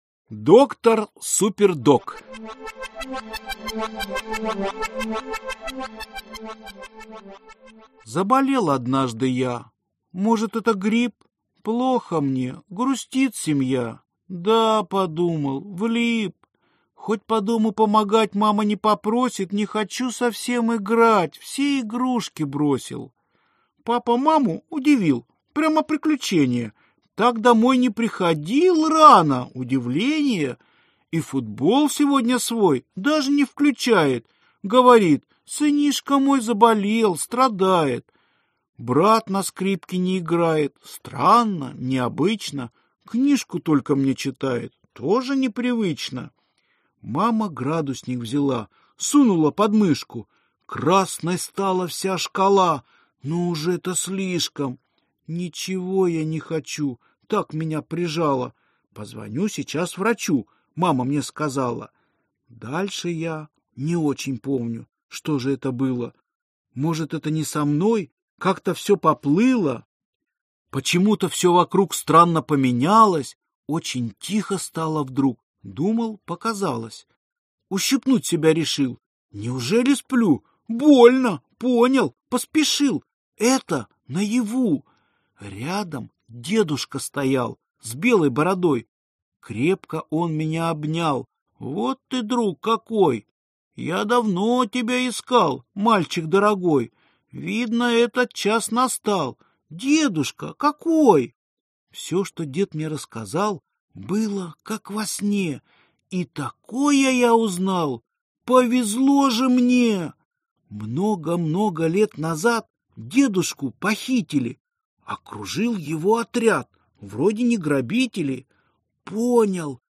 Аудиокнига Доктор Супердок | Библиотека аудиокниг